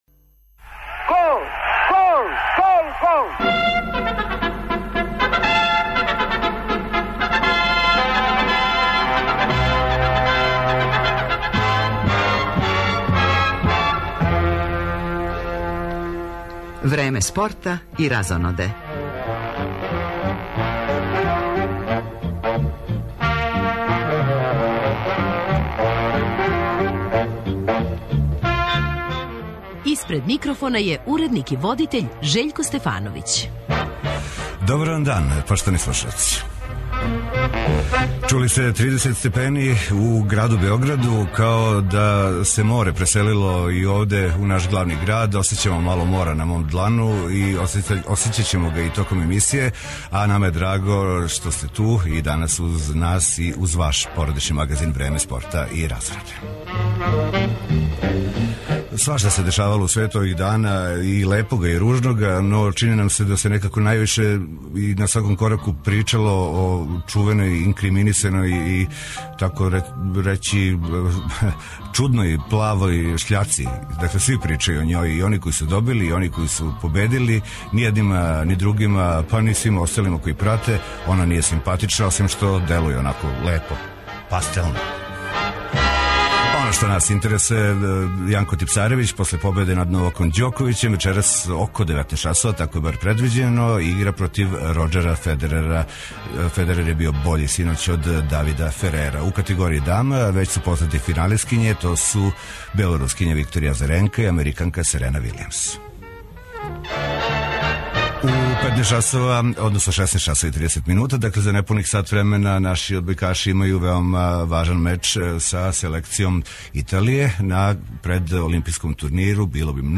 Породични магазин Време спорта и разоноде, као и сваке суботе, представља занимљиве госте из света спорта, забаве, музике, јавног и културног живота. Од 17 часова комбиновано ћемо преносити утакмице претпоследњег кола Супер лиге Србије у фудбалу, уз заслужене честитке фудбалерима Партизана на новој шампионској титули.
Гошћа у студију Радио Београда 1 биће врсна певачица Мери Цетинић, која је тренутно на концертној турнеји по Србији.